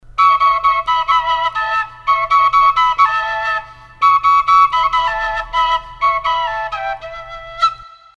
Двоянка D/Em
Двоянка D/Em Тональность: D/Em
Двоянка - болгарская флейта, имеющая два канала, позволяющих извлекать двойное звучание. Один используется как бурдонный, без игровых отверстий.
Общий строй в миноре, игровой канал настроен в мажоре.